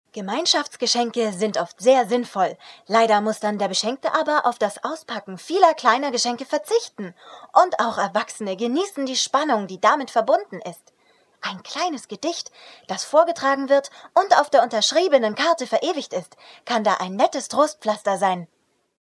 Schauspielerin, Moderatorin und Sprecherin
Sprechprobe: Werbung (Muttersprache):